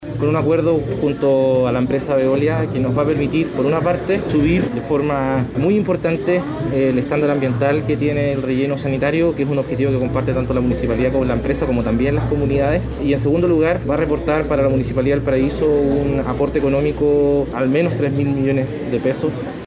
Tras la firma del nuevo acuerdo, el alcalde, Jorge Sharp, afirmó que se incrementará el estándar medioambiental del relleno.